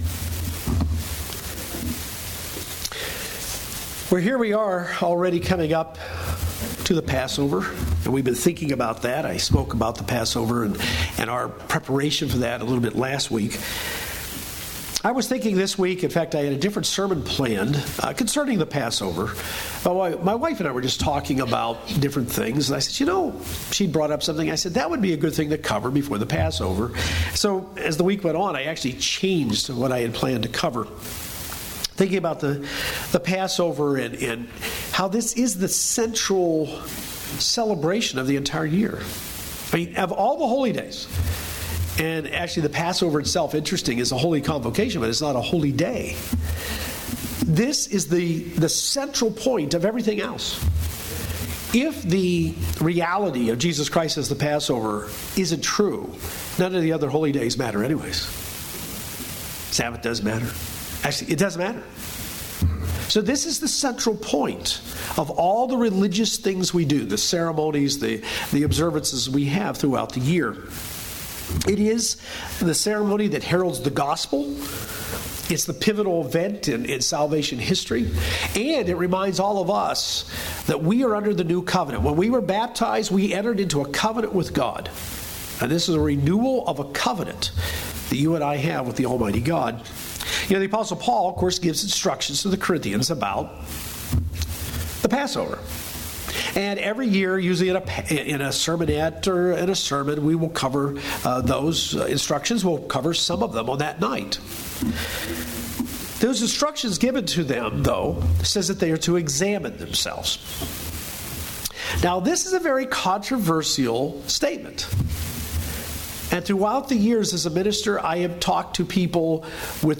This sermon zeroes in on that instruction with three questions we should ask ourselves before keeping the Passover.